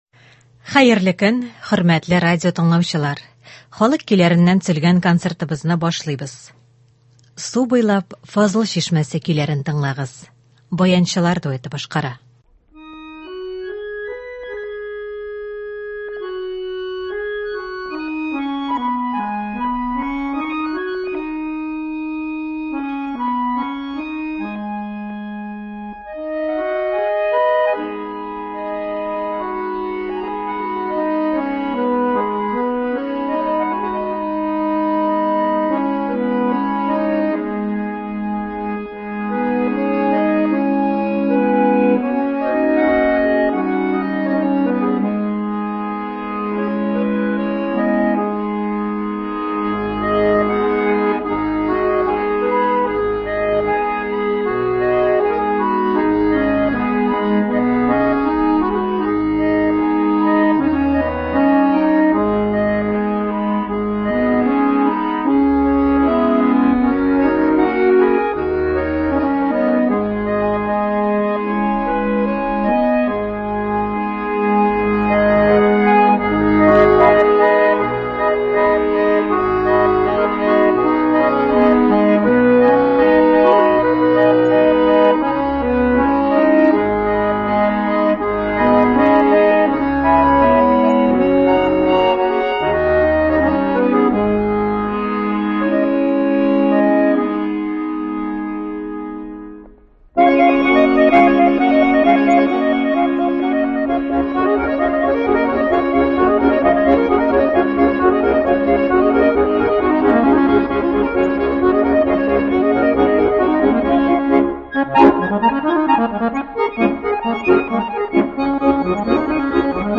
Татар халык җырлары гасырлардан килә.
Бүген без сезнең игътибарга радио фондында сакланган җырлардан төзелгән концерт тыңларга тәкъдим итәбез.